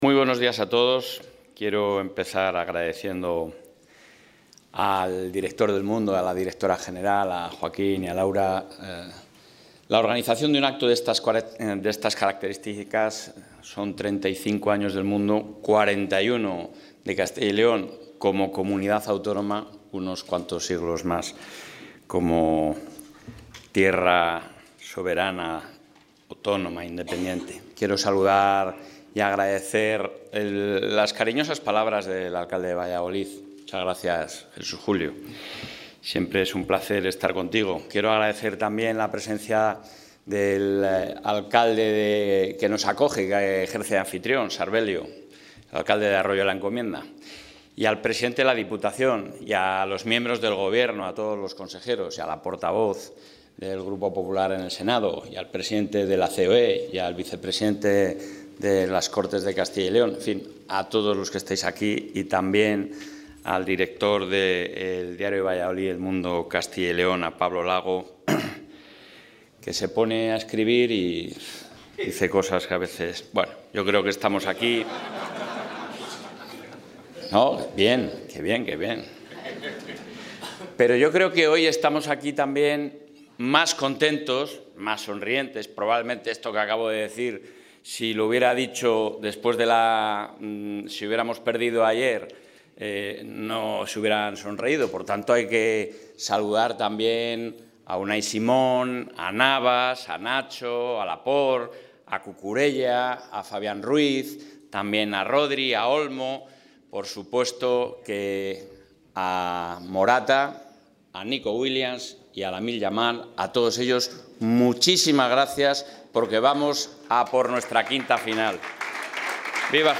Intervención del presidente de la Junta.
El presidente de la Junta de Castilla y León, Alfonso Fernández Mañueco, ha participado hoy en Valladolid en el foro autonómico organizado por El Mundo con motivo de su XXXV aniversario, donde ha destacado el indudable protagonismo de la Comunidad en la vertebración de España, gracias, entre otros aspectos, a la visión integradora y solidaria que ofrece.